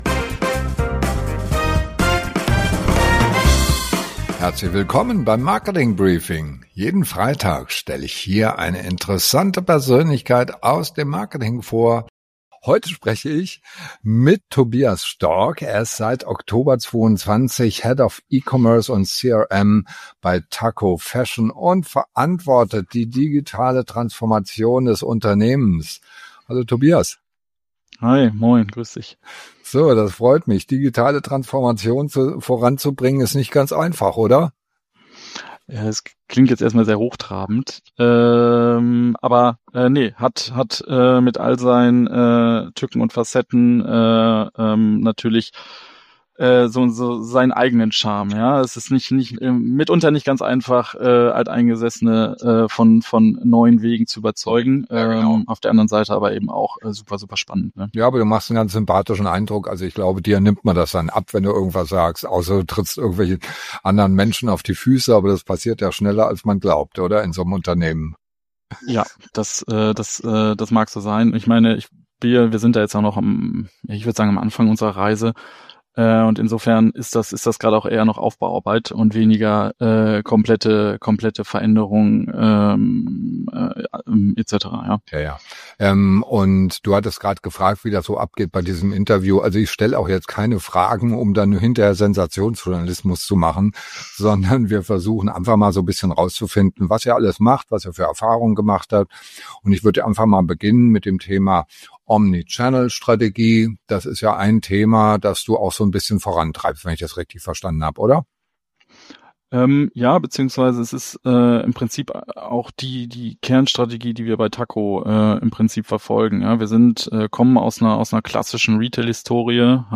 Jeden Freitag Interviews mit spannenden Persönlichkeiten aus der Digital- & Marketing-Szene